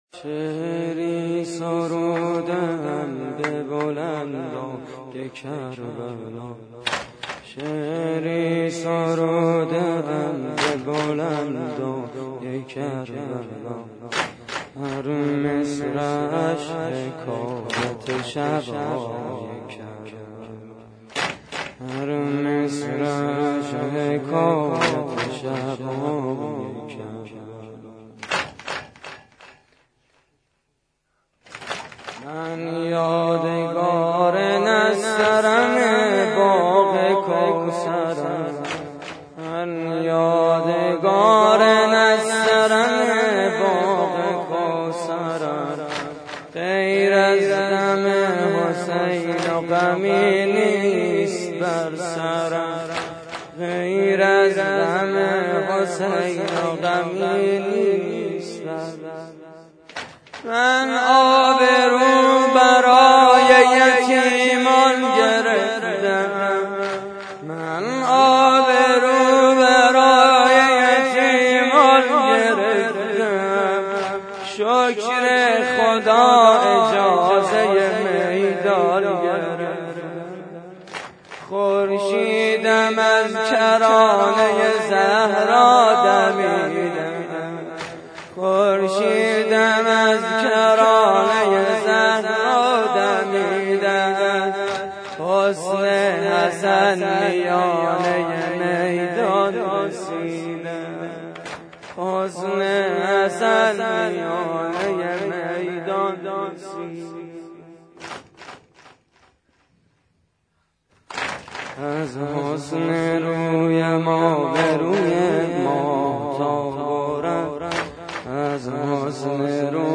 مراسم عزاداری شب ششم ماه محرم / هیئت کریم آل طاها (ع) – شهرری؛ 1 دی 88
واحد: شعری سروده‌ام به بلندای کربلا